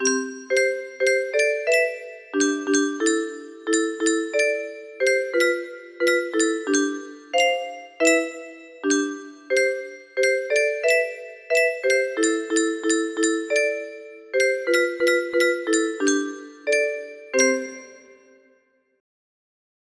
Sarie Marais - 105 beats music box melody